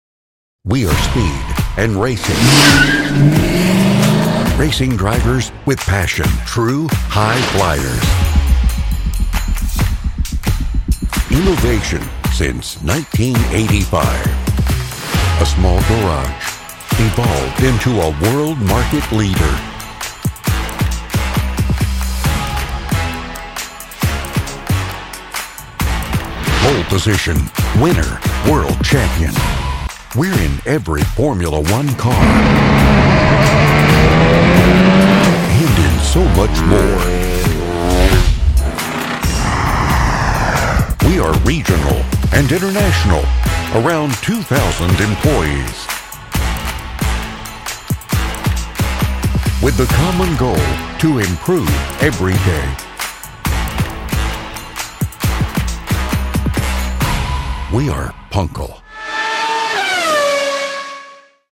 Male
English (North American)
Adult (30-50), Older Sound (50+)
All our voice actors have professional broadcast quality recording studios.
1001Corporate_Demo.mp3